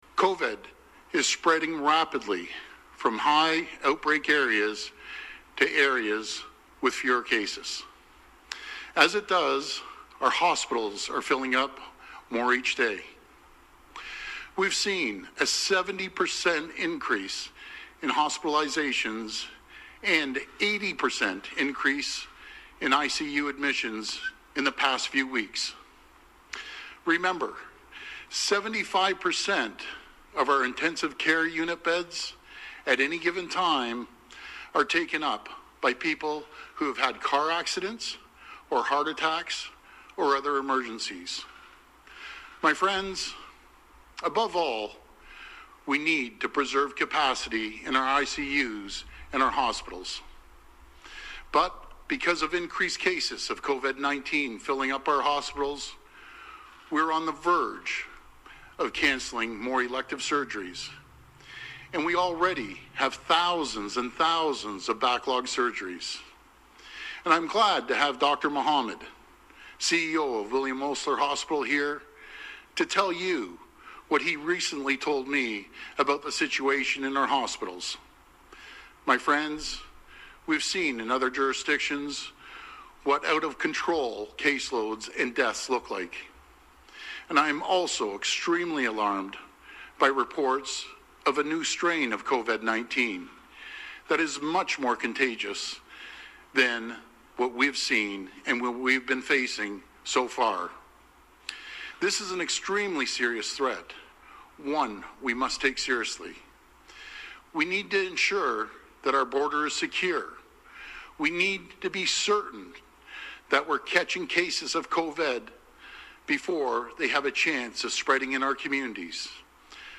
Premier Ford audio